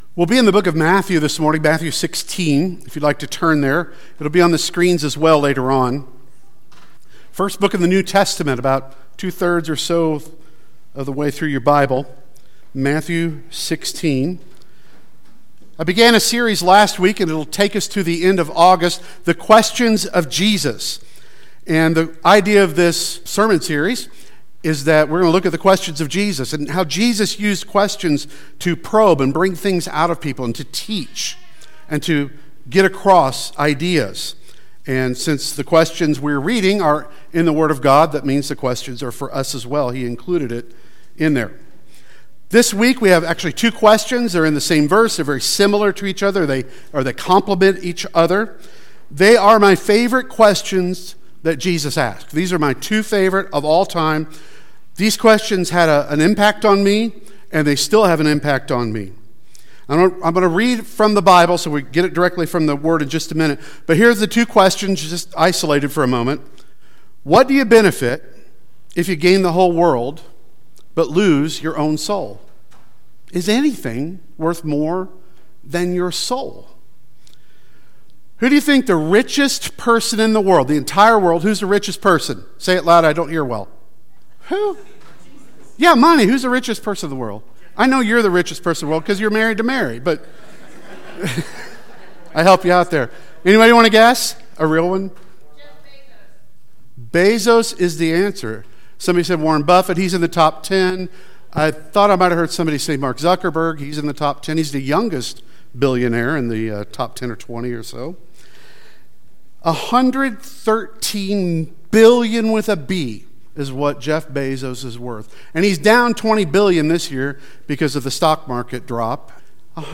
The Questions of Jesus Service Type: Sunday Worship Service Preacher